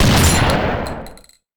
rifle.wav